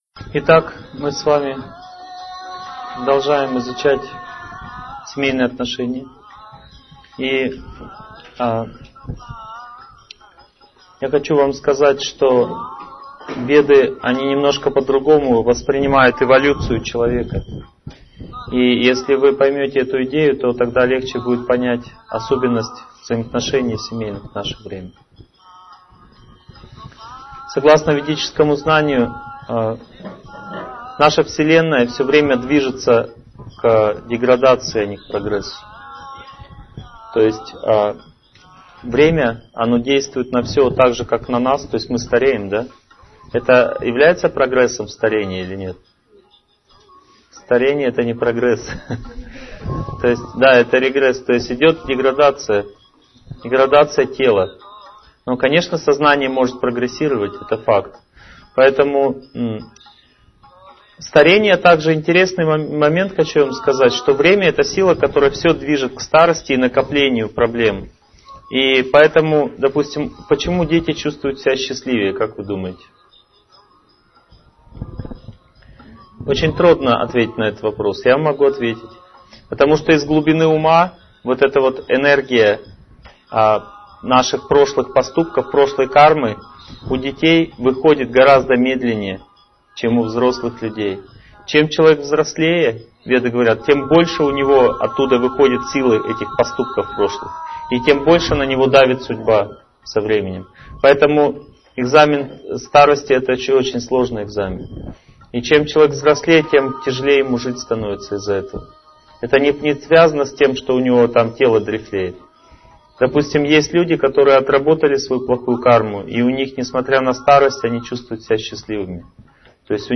Аудиокнига Как уберечь партнера в наше сложное время | Библиотека аудиокниг